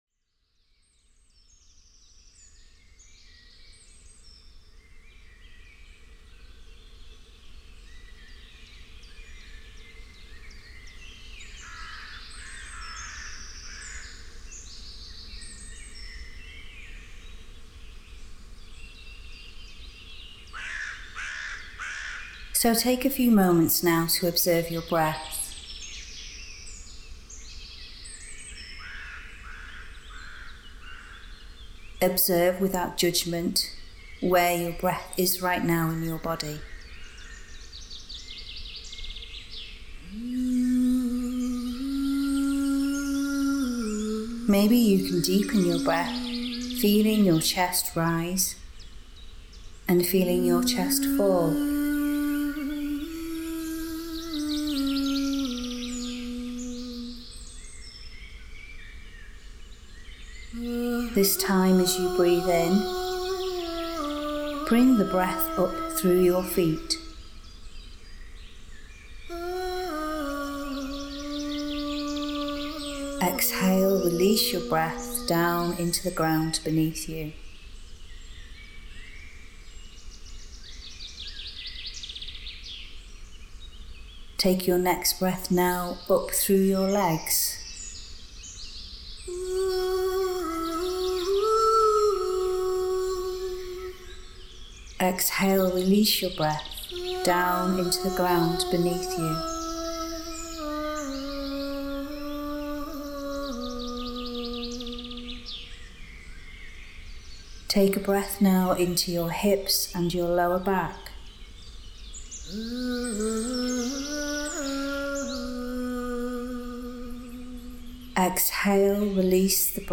gathering-fields-meditation-natural-sounds.mp3